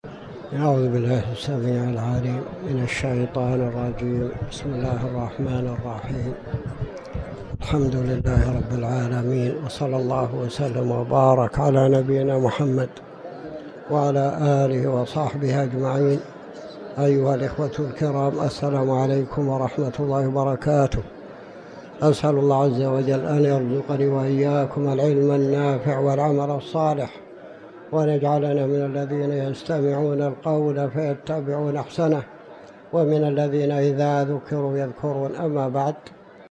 تاريخ النشر ٦ جمادى الآخرة ١٤٤٠ هـ المكان: المسجد الحرام الشيخ